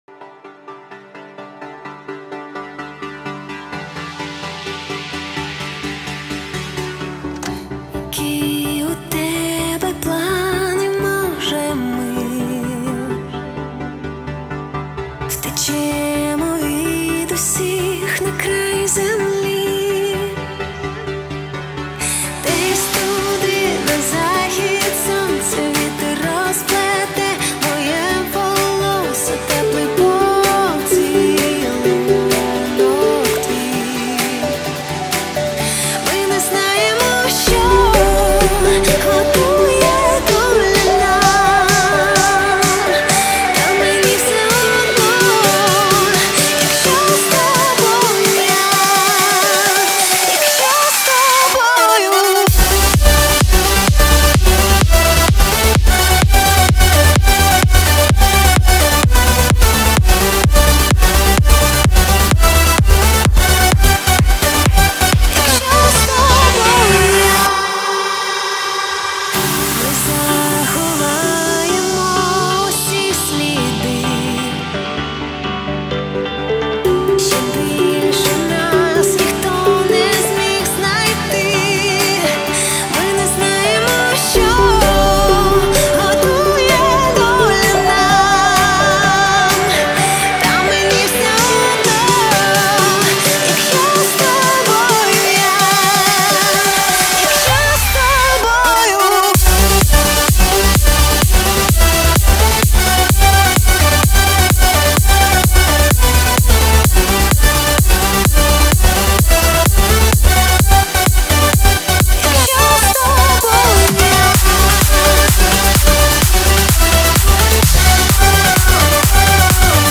Всі мінусовки жанру Remix
Плюсовий запис